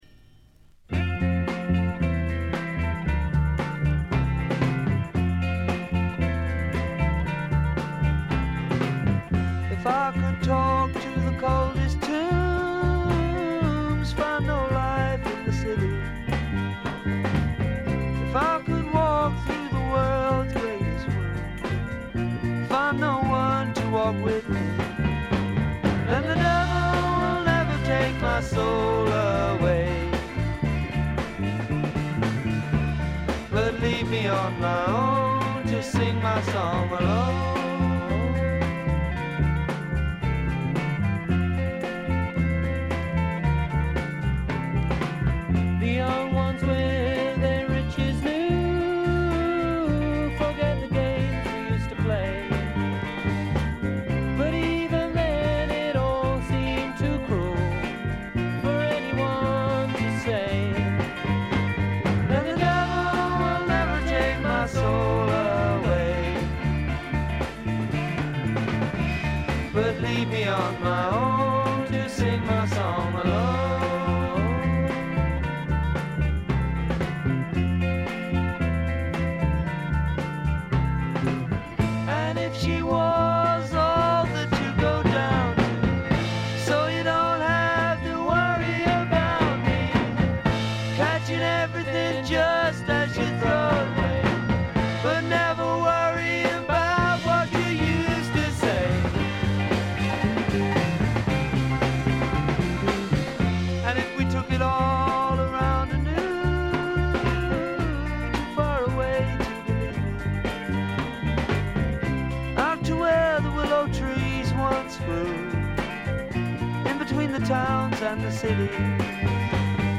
静音部で軽微なチリプチやバックグラウンドノイズ。
非トラッド系英国フォーク至宝中の至宝。
試聴曲は現品からの取り込み音源です。